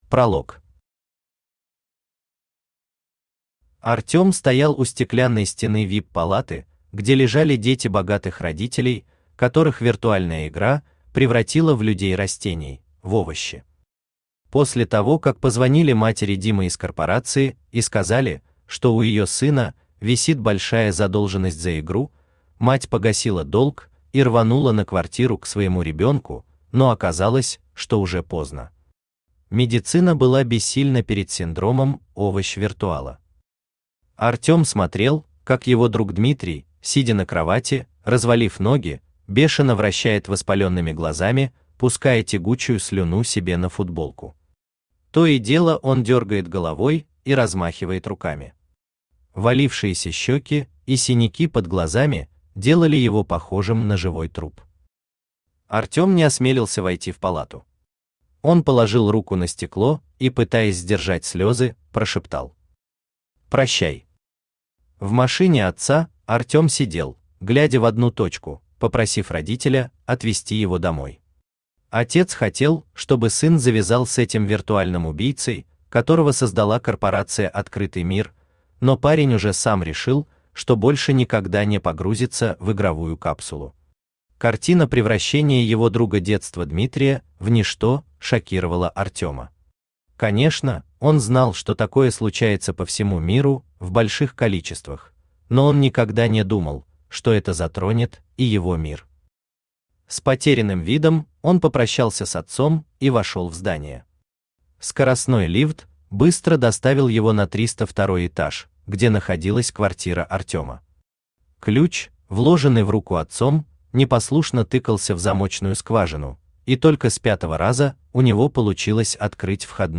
Аудиокнига 99 уровень. Предреальность | Библиотека аудиокниг
Предреальность Автор Сергей Витальевич Шакурин Читает аудиокнигу Авточтец ЛитРес.